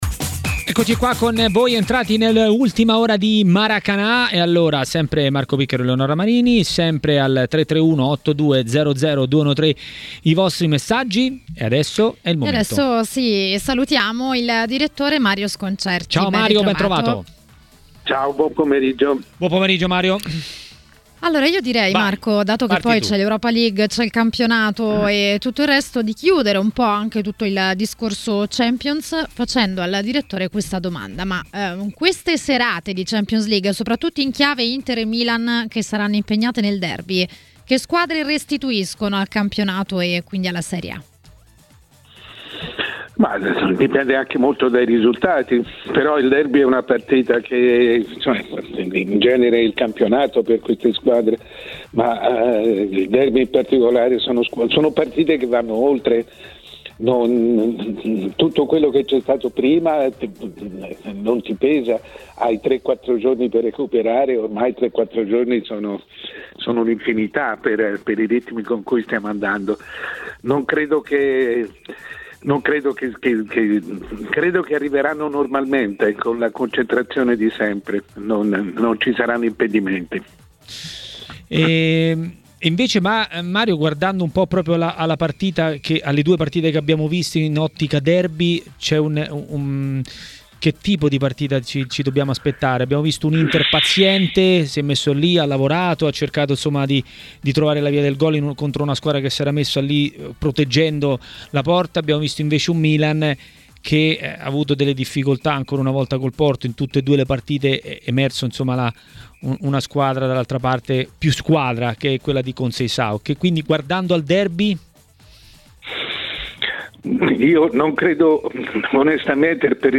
A TMW Radio, durante Maracanà, il direttore Mario Sconcerti ha parlato delle italiane in Champions e non solo.